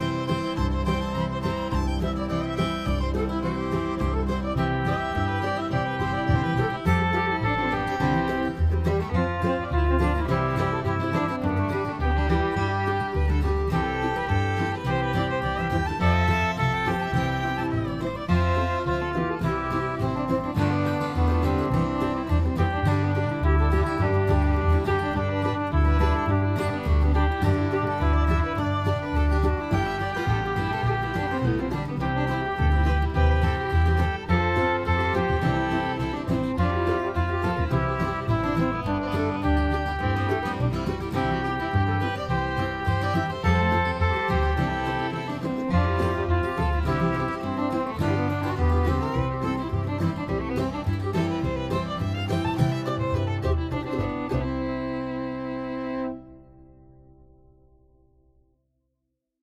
Instrumentale versie
getellanlied-instr.mp3